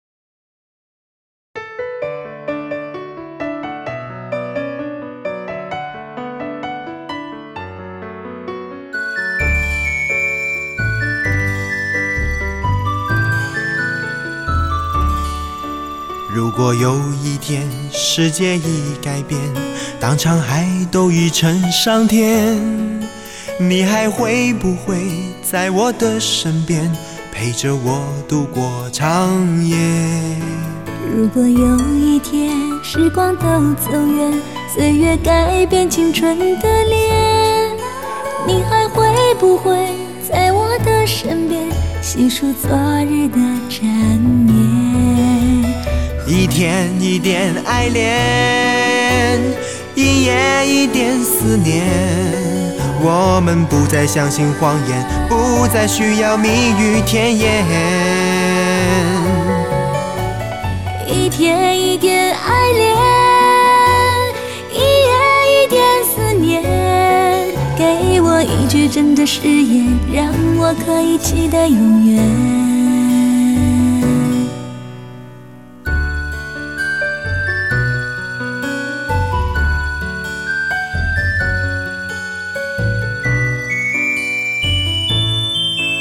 高亢的嗓音，投入的表演，